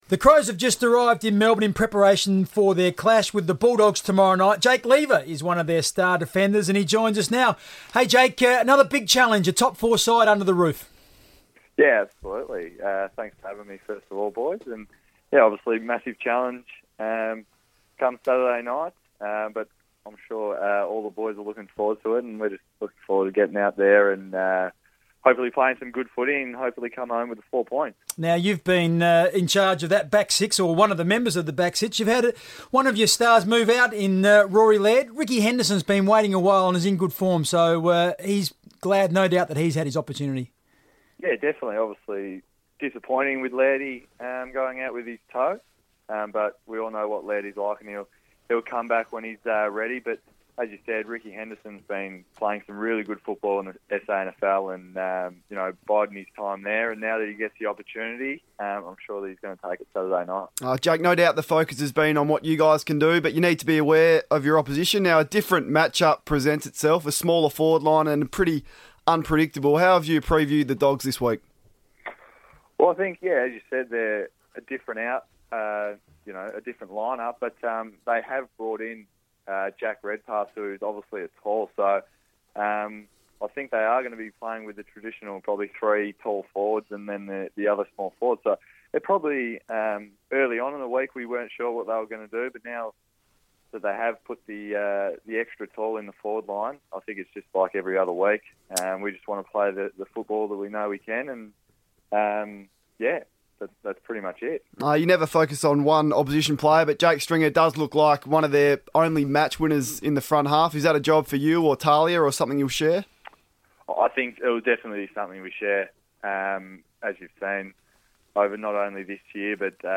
Defender Jake Lever spoke on FIVEaa radio ahead of Adelaide's clash with the Western Bulldogs